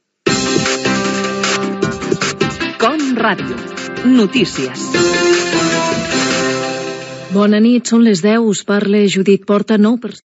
Careta del programa i salutació inicial Gènere radiofònic Informatiu